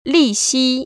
[lìxī] 리시